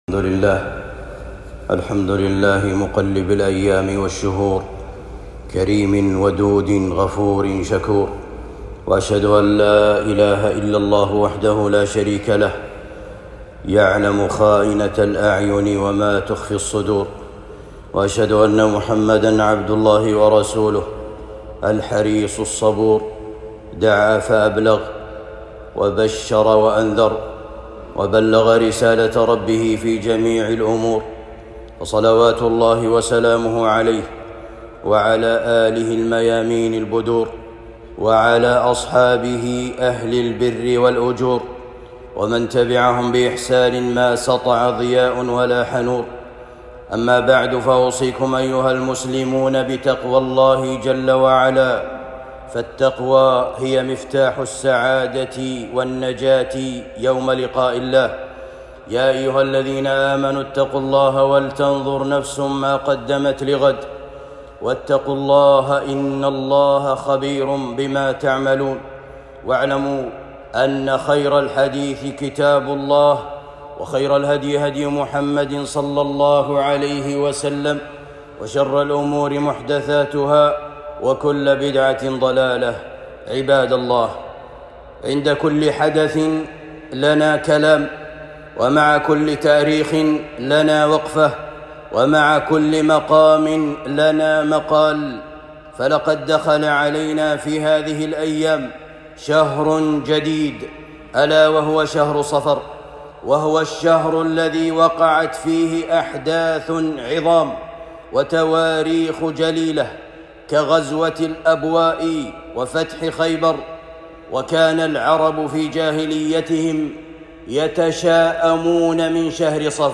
خطبة جمعة بعنوان نبذ التشاؤم في هذا الشهر (صفر)